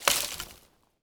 wood_tree_branch_move_01.wav